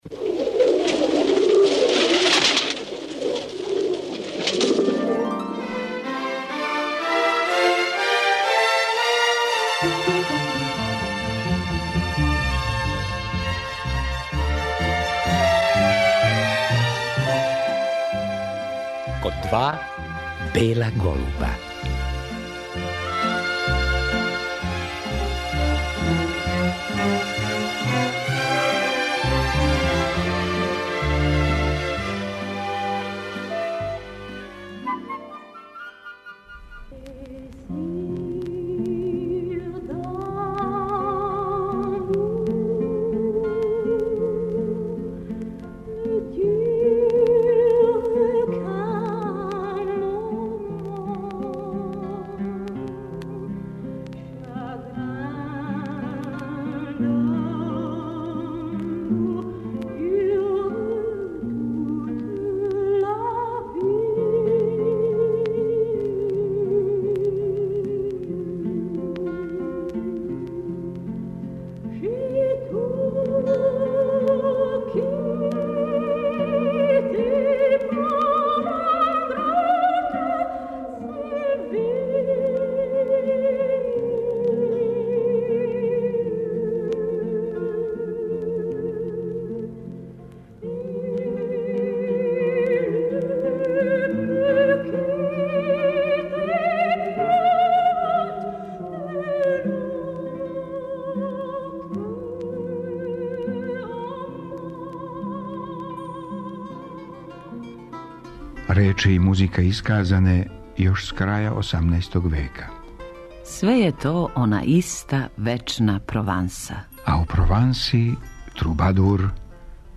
Песму Santa Maria della Salute казивао је Петар Краљ.